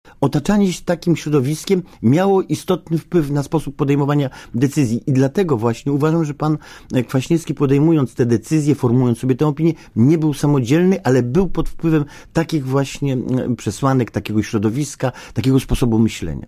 Mówi Antoni Macierewicz
macierewicz_o_kwasniewskim.mp3